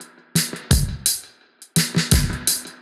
Index of /musicradar/dub-designer-samples/85bpm/Beats
DD_BeatA_85-03.wav